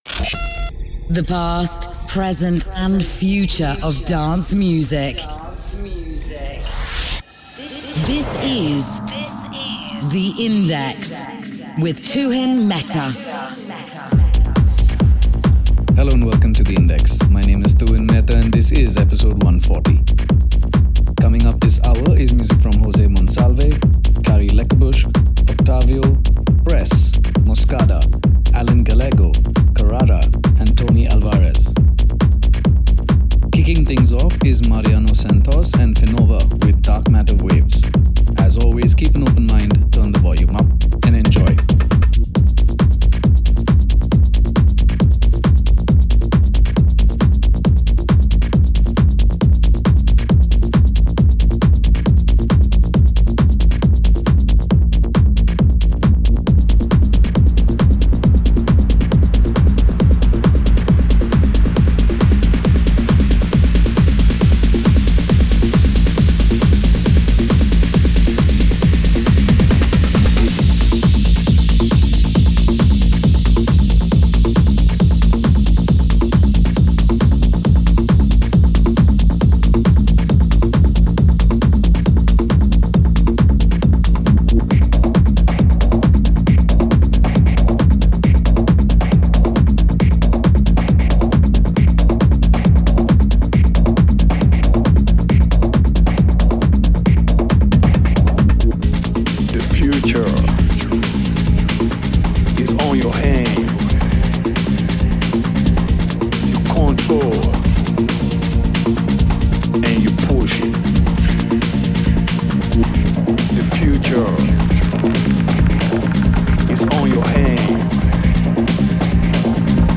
Techno Tech Dance